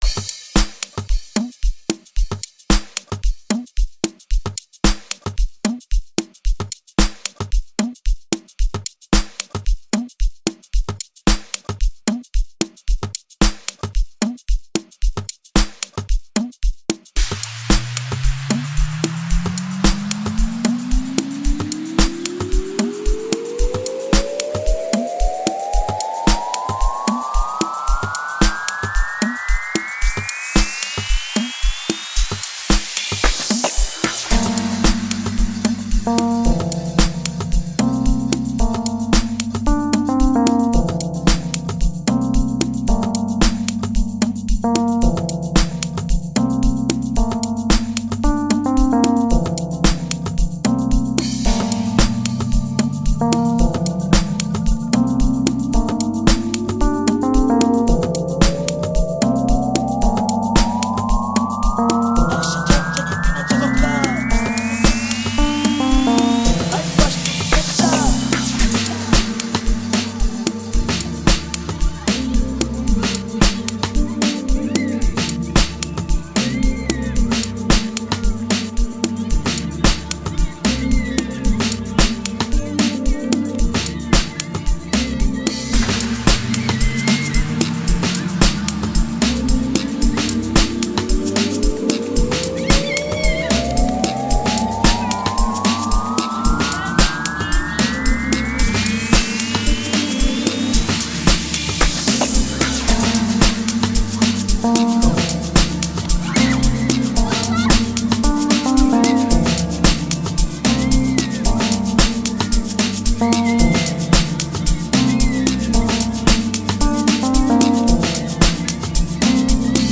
an extremely catchy new house single
complete with a dance-along rhythm and flows.